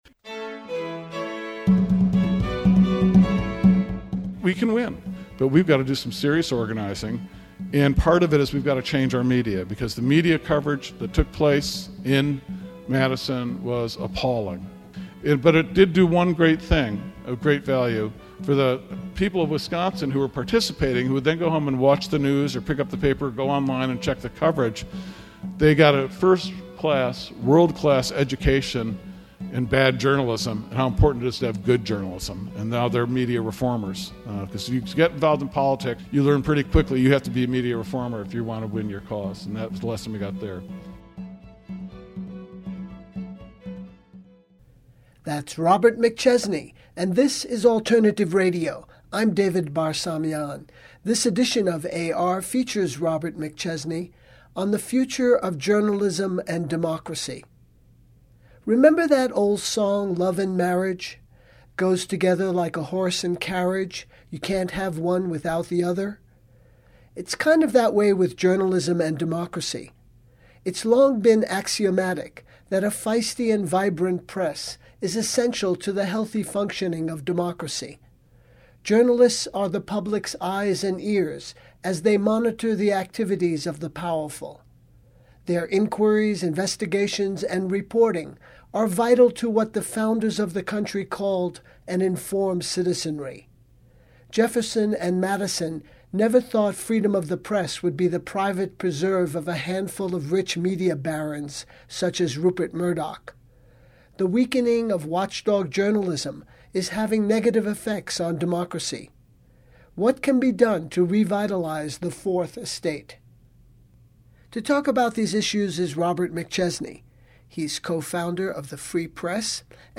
Robert McChesney The Future of Journalism & Democracy (lecture)